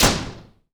WOOD SLAM -S.WAV